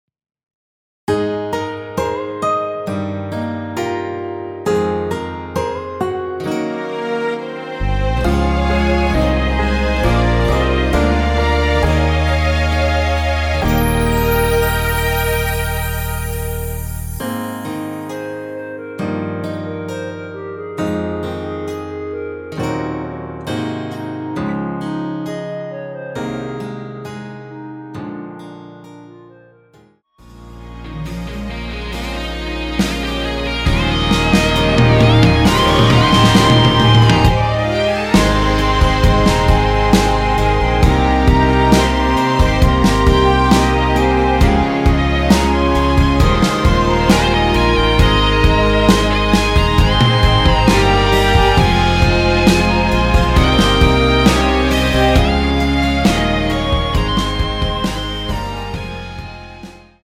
원키에서(+5)올린 멜로디 포함된 MR입니다.
Ab
앞부분30초, 뒷부분30초씩 편집해서 올려 드리고 있습니다.
중간에 음이 끈어지고 다시 나오는 이유는